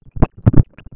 Declaraciones del Pte. Dr.  Jorge Batlle a la prensa uruguaya,